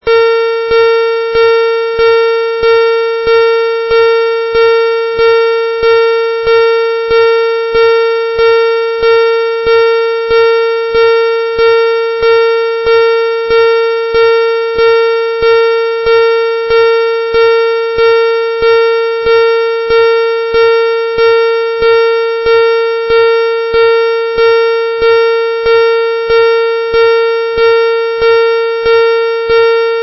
音响发铁路道口 常用警钟声，或警钟声加“小心火车，注意安全”的语音提示声。
警钟